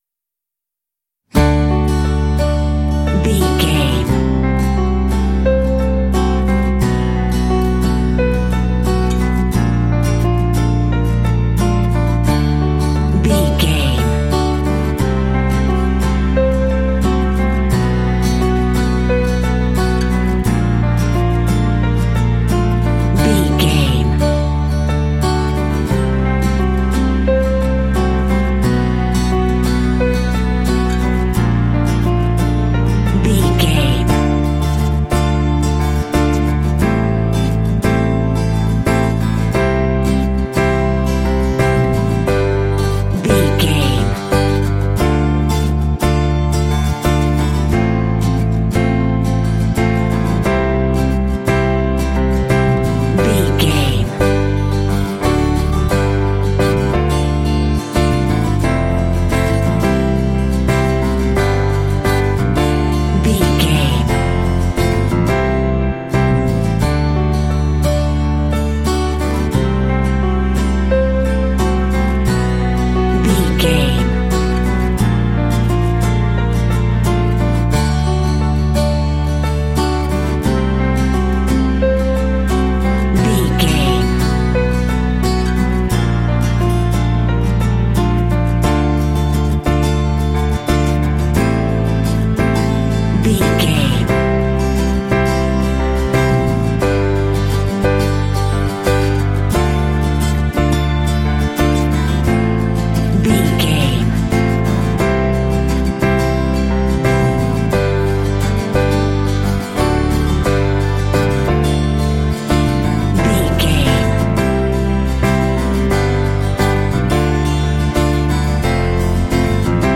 Ionian/Major
light
dreamy
sweet
orchestra
horns
strings
percussion
cello
acoustic guitar
cinematic
pop